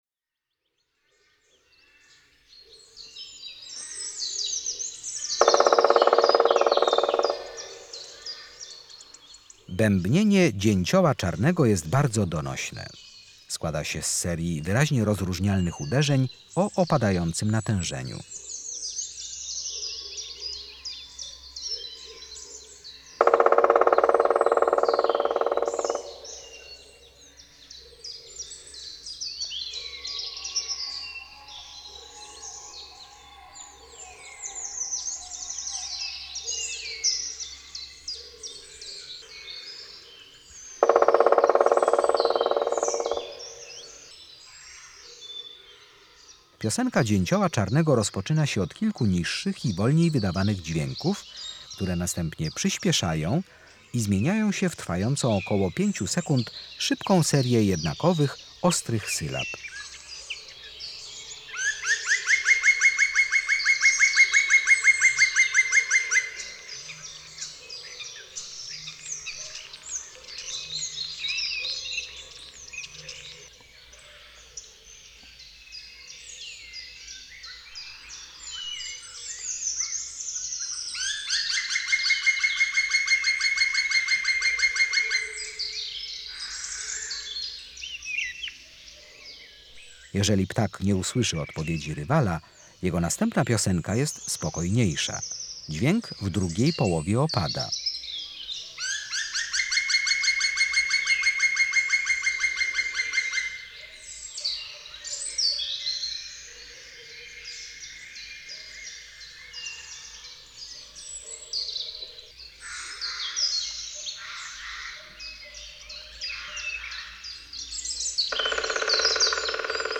17-Dzieciol czarny.mp3